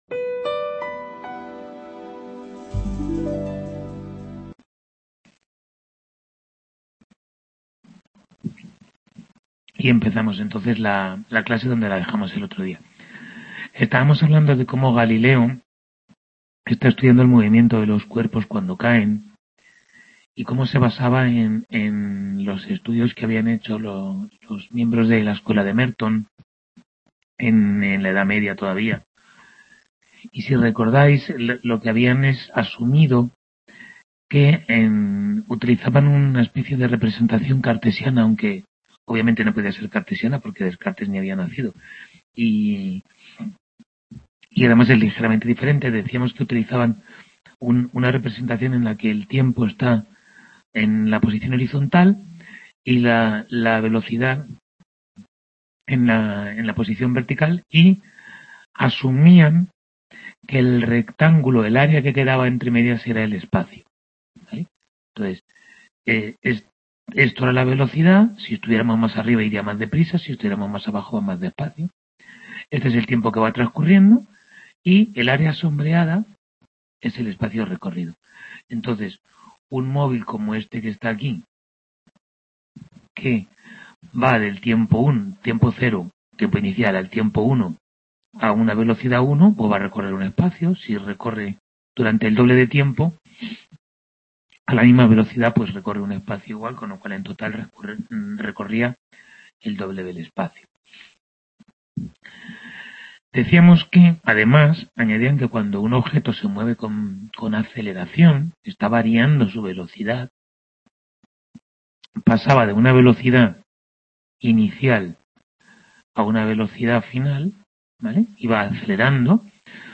Tutoría 10 de Historia General de la Ciencia I